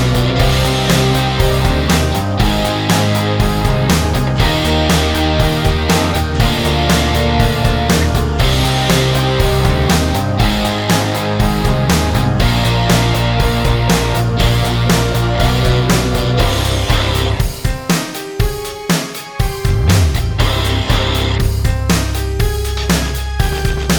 Minus Lead And Solo Guitar Rock 3:48 Buy £1.50